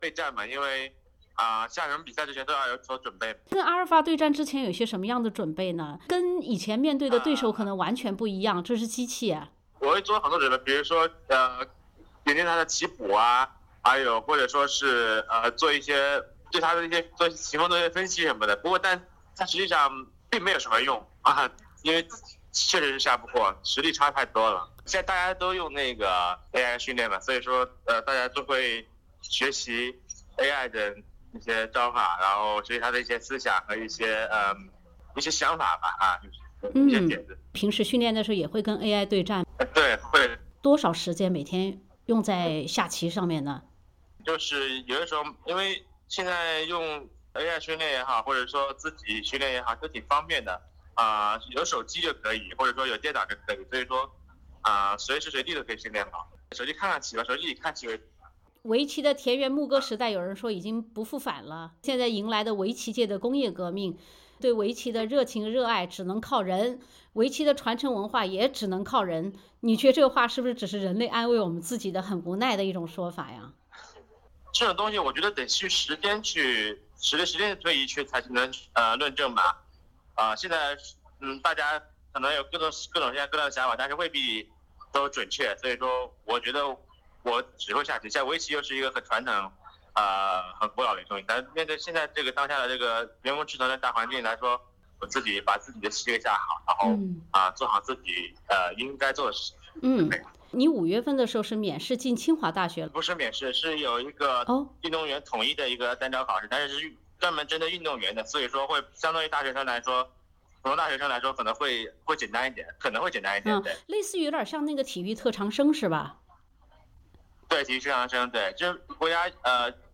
【SBS独家】柯洁专访：确实下不过“阿尔法狗”，实力差太多